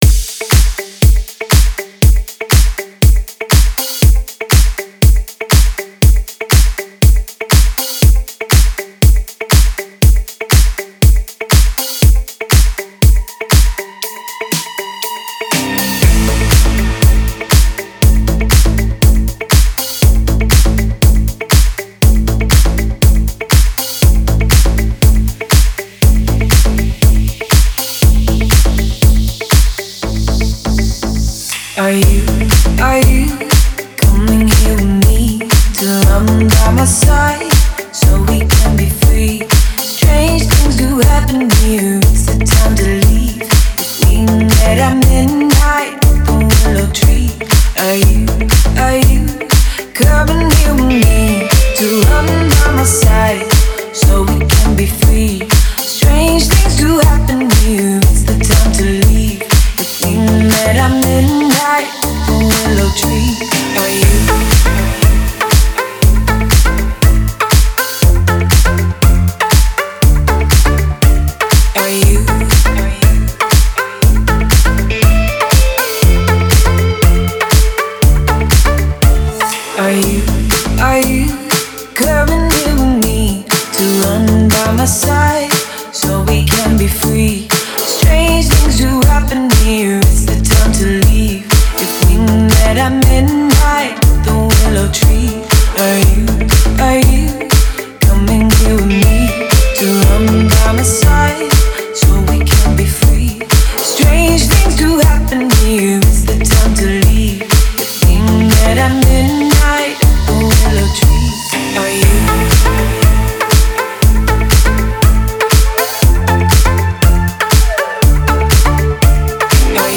это энергичный трек в жанре прогрессив-хаус